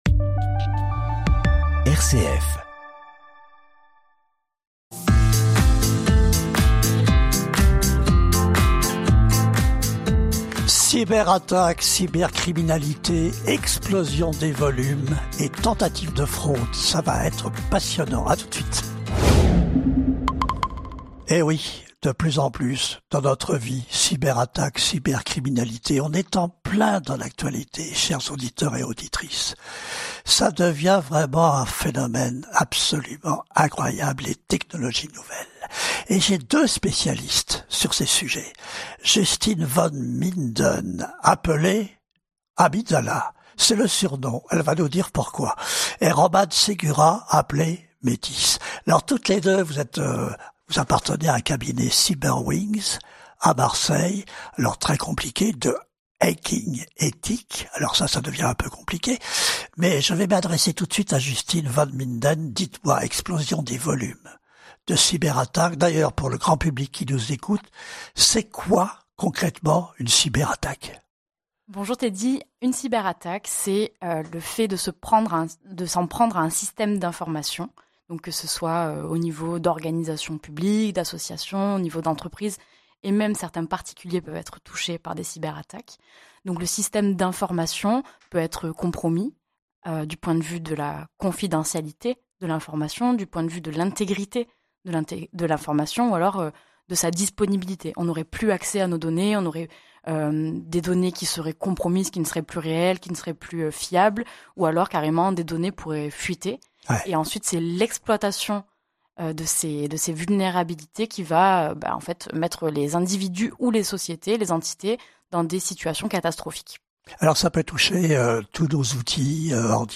Une interview de nos chroniqueuses préférées d'OnCybAir sur RCF Vaucluse. Cyber-attaque, cyber-criminalité et impacts sur l'usage du numérique sont tant de sujet abordés pendant les 45 min.